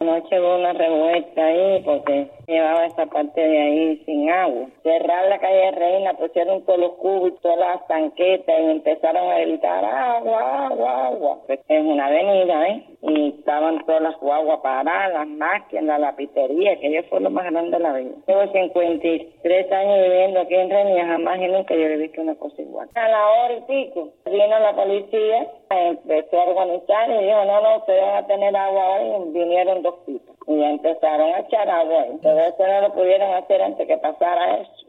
"La gente empezó a gritar agua, agua, agua.... Tengo 53 años y jamás he visto una cosa igual", dijo una residente a nuestra redacción.
Vecinos de Centro Habana interrumpieron el tránsito en la calle Reina